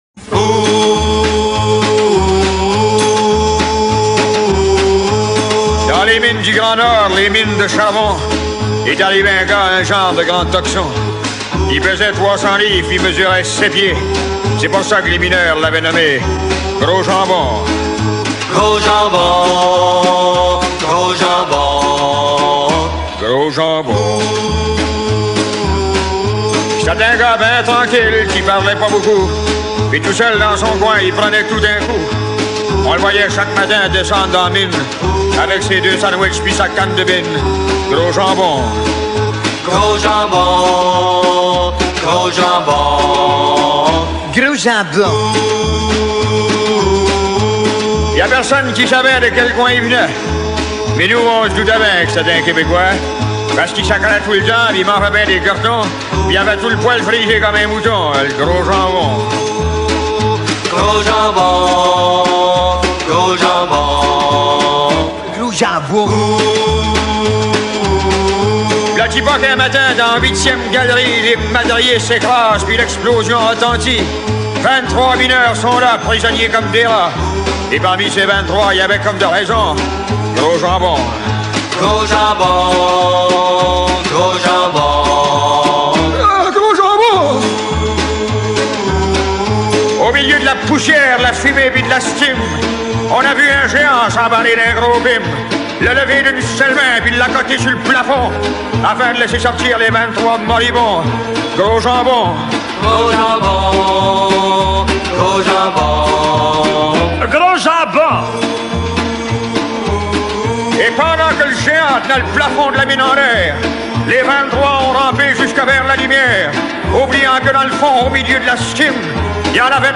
le style parlé de la pièce a certainement aidé